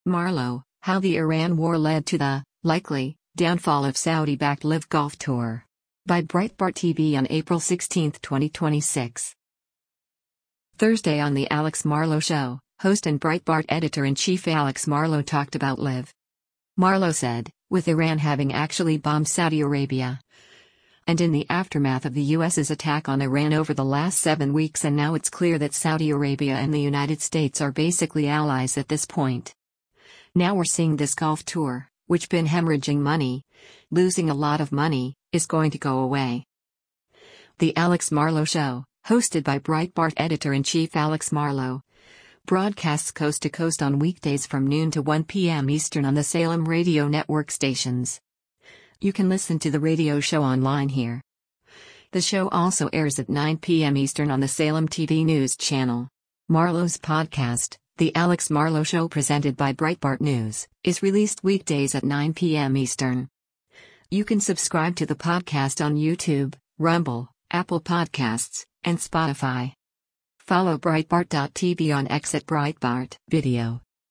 Thursday on “The Alex Marlow Show,” host and Breitbart Editor-in-Chief Alex Marlow talked about LIV.